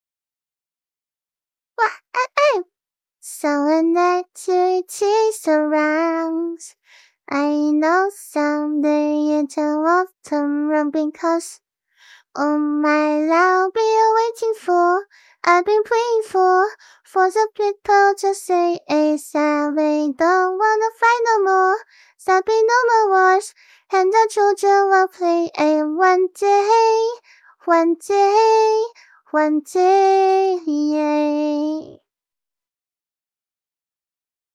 唱歌效果